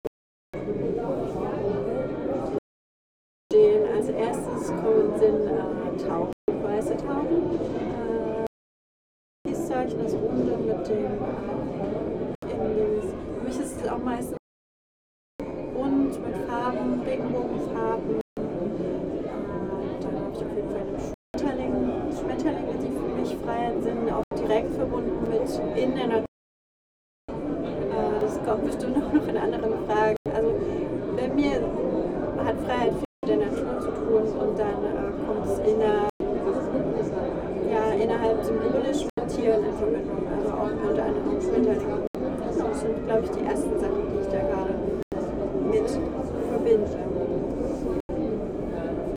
Welche Bilder und Symbole stehen für Dich für Freiheit [Anmerkung der Redaktion: schlechte Soundqualität]
Stendal 89/90 @ Stendal